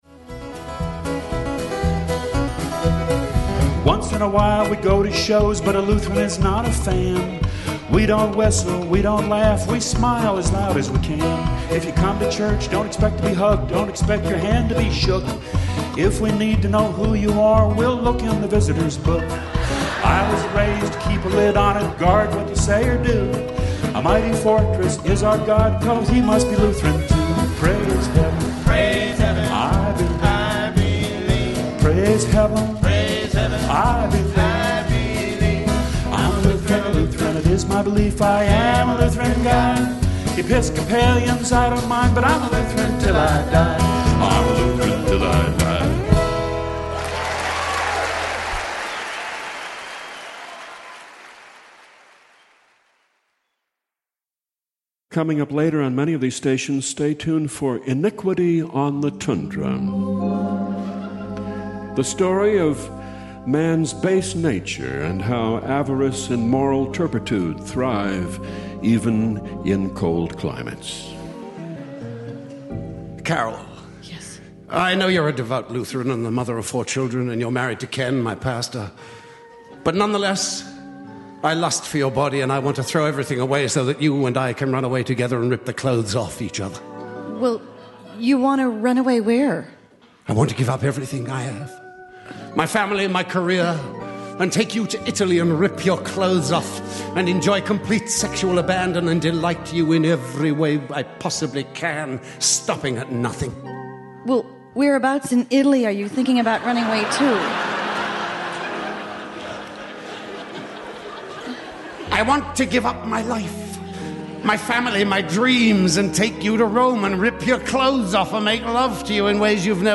VIEW ALL EVENTS Listen to this 2006 classic Grandstand show We’ve got a blue-ribbon winner of a show for you this week from the Grandstand of the Minnesota State Fair.